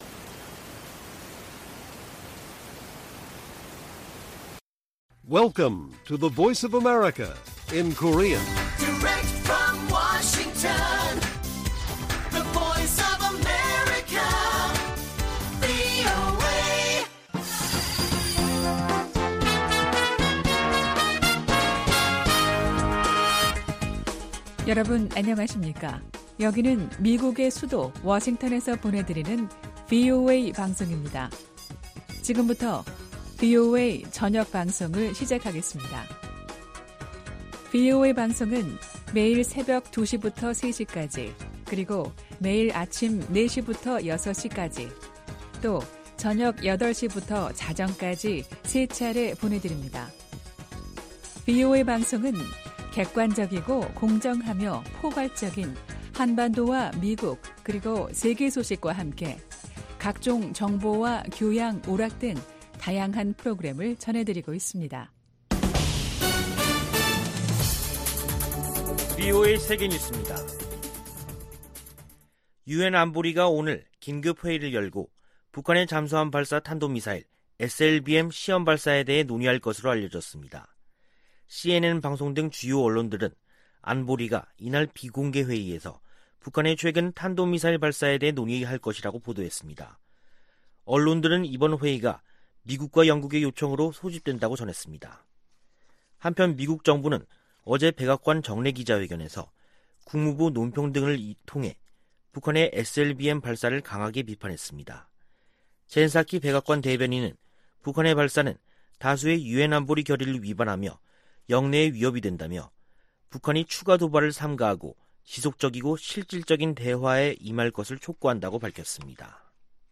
VOA 한국어 간판 뉴스 프로그램 '뉴스 투데이', 2021년 10월 20일 1부 방송입니다. 미국 정부는 북한의 탄도미사일 발사에 우려하며 조속히 대화에 나설 것을 촉구했습니다. 유엔은 북한 미사일과 관련, 국제 의무를 준수하고 외교노력을 재개하라고 촉구했습니다. 최근 미국과 한국, 일본 고위 당국자들의 잇따른 회동에 관해 미국의 전문가들은 불투명한 한반도 상황 속에서 협력을 강화하고 견해 차를 좁히려는 움직임으로 분석했습니다.